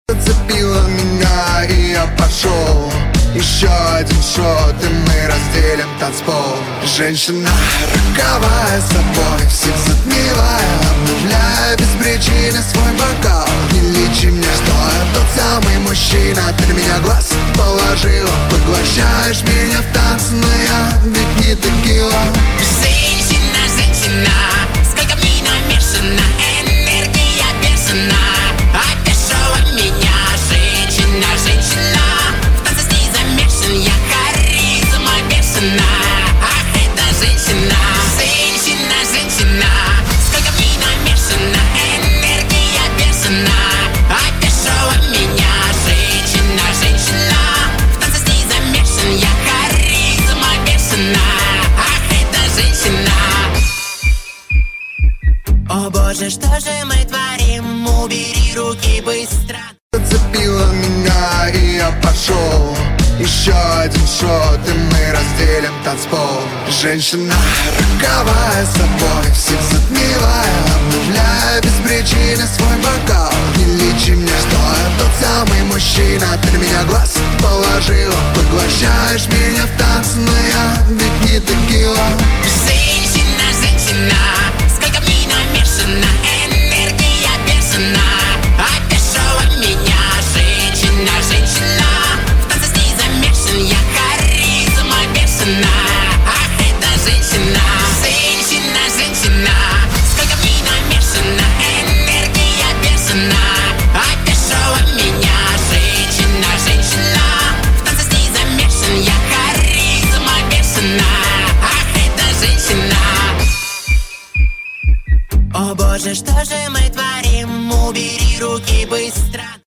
Качество: 320 kbps, stereo
Поп музыка, Танцевальная музыка